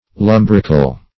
Search Result for " lumbrical" : The Collaborative International Dictionary of English v.0.48: Lumbrical \Lum"bric*al\, a. [Cf. F. lombrical.